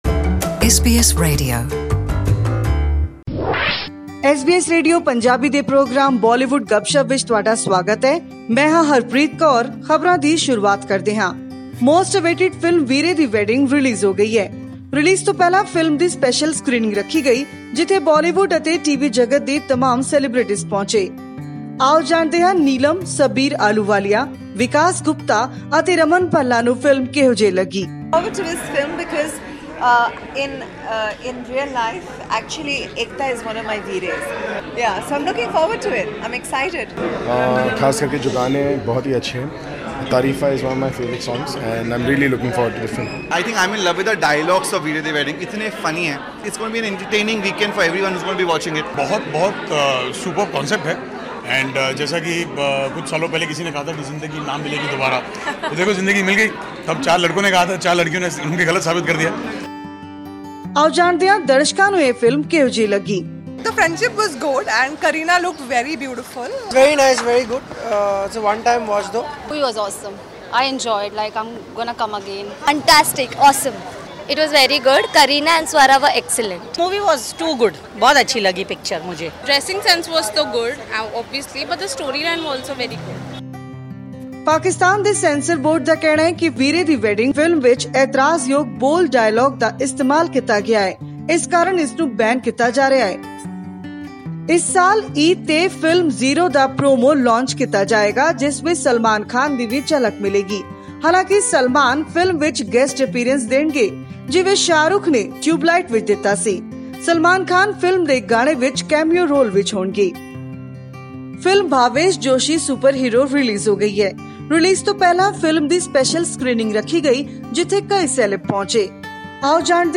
ਕਈ ਹੋਰ ਫਿਲਮਾਂ ਦੇ ਹਿੱਟ ਗੀਤਾਂ ਦੇ ਮੁਖੜੇ ਸੁਨਣ ਵਾਸਤੇ , ਜੁੜੋ ਇਸ ਬੋਲੀਵੁੱਡ ਗੱਪਸ਼ੱਪ ਨਾਲ ।